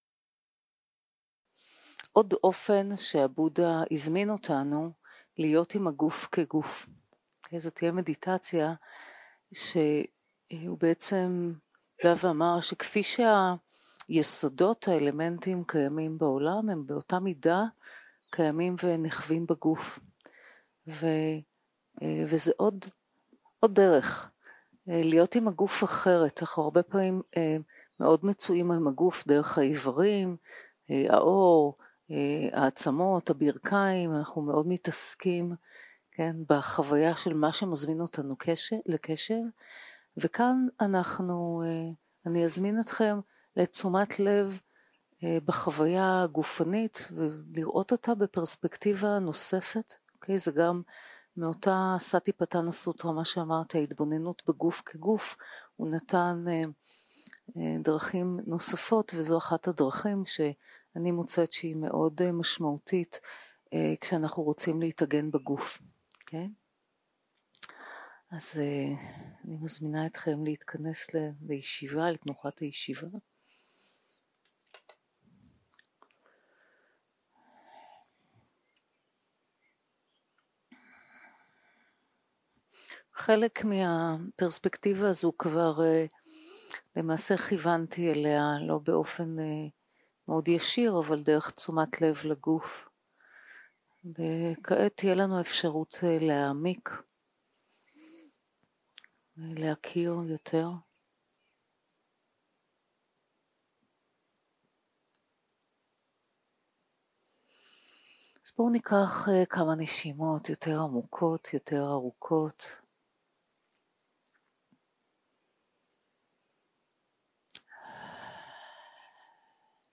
יום 2 - הקלטה 3 - צהרים - הנחיות למדיטציה - ארבעת האלמנטים 0:00 0:00 סוג ההקלטה: Dharma type: Guided meditation שפת ההקלטה: Dharma talk language: Hebrew